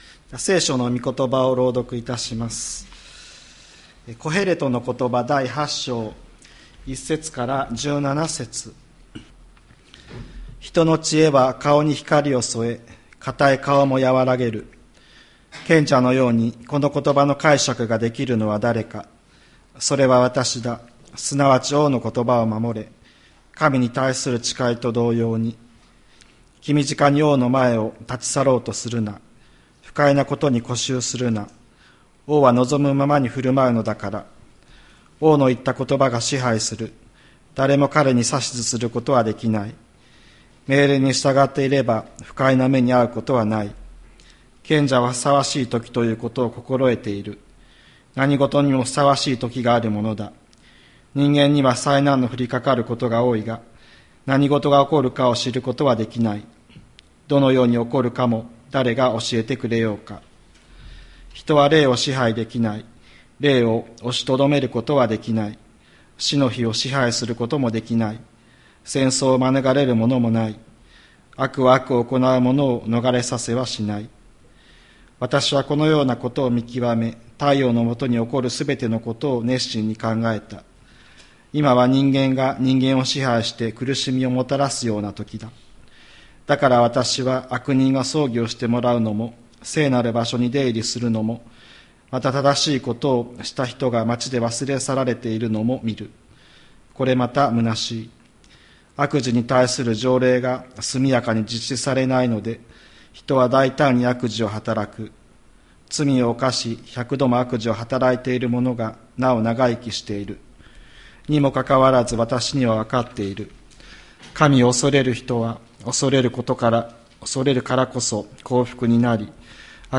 千里山教会 2024年11月24日の礼拝メッセージ。